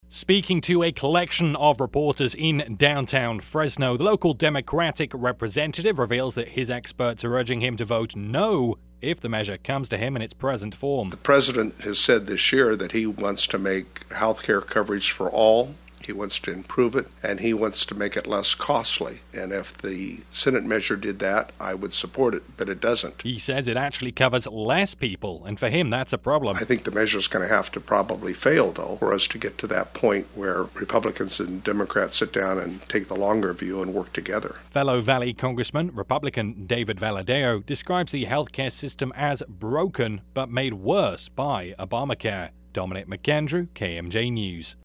as it aired